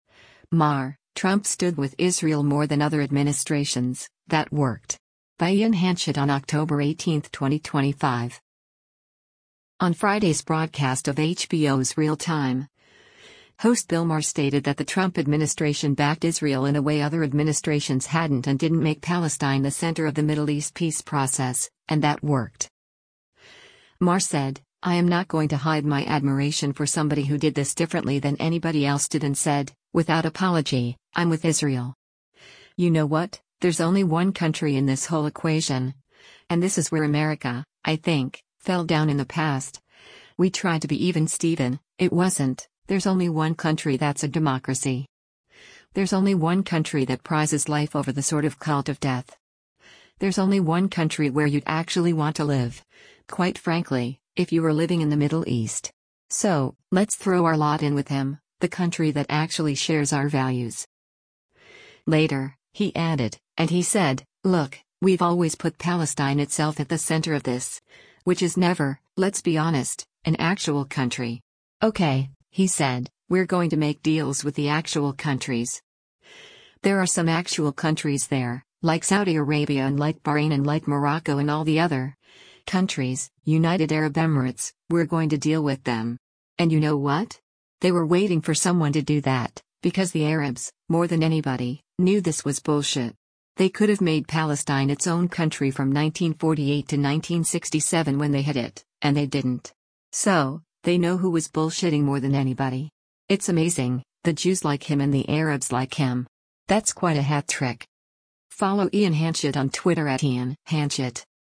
On Friday’s broadcast of HBO’s “Real Time,” host Bill Maher stated that the Trump administration backed Israel in a way other administrations hadn’t and didn’t make Palestine the center of the Middle East peace process, and that worked.